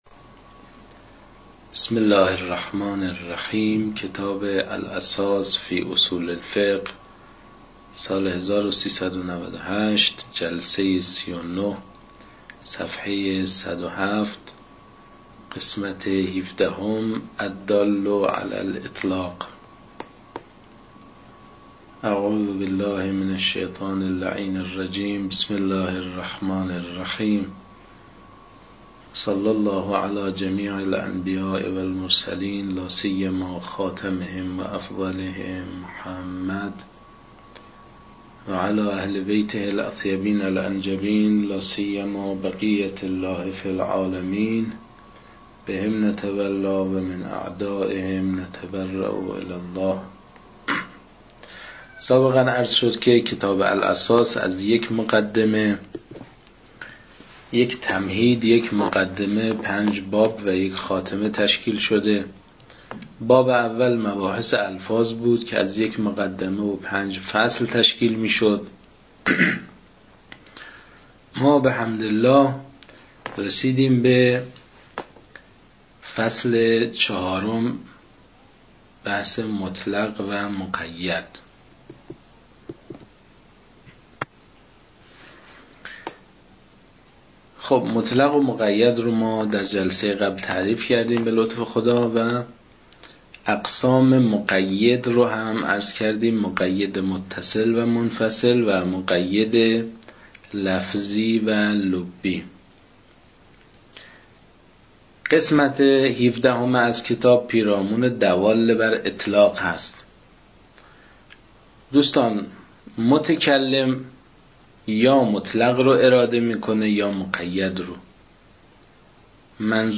صوت‌های تدریس